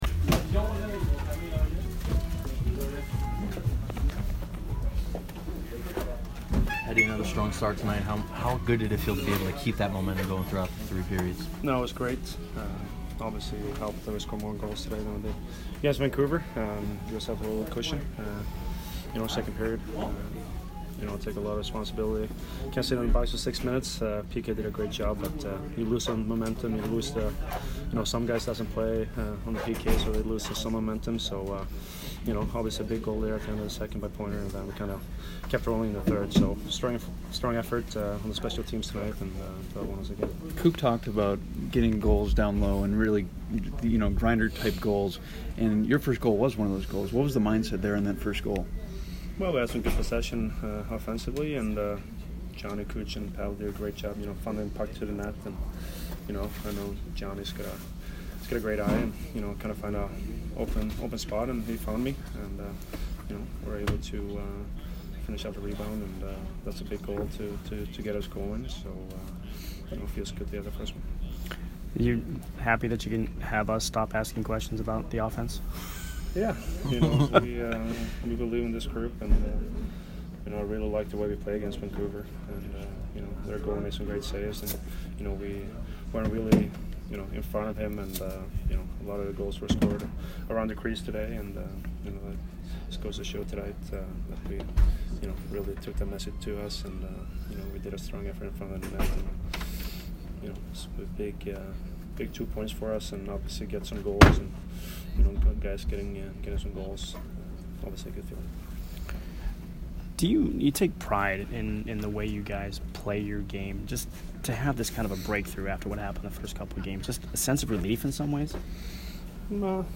Victor Hedman post-game 10/13